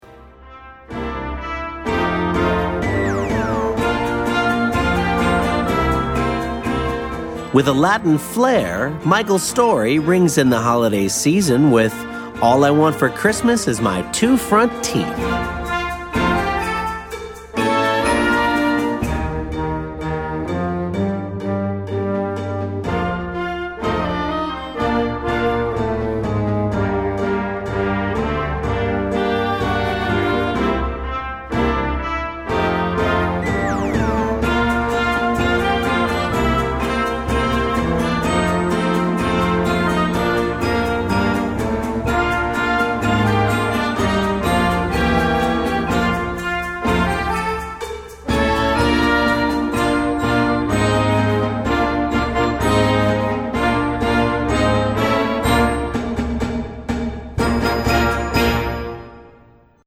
Werk für Jugendblasorchester
Besetzung: Blasorchester